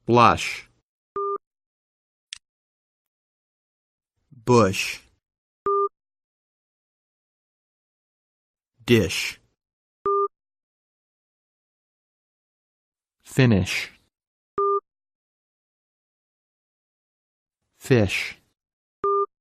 Hơi  từ phổi thoát qua khe hở lưỡi – nóc tạo âm: “shì…”
• Là âm hơi (voiceless sound) dây thanh âm mở nên khi phát âm, sờ lên cổ không cảm thấy rung.
Luyện tập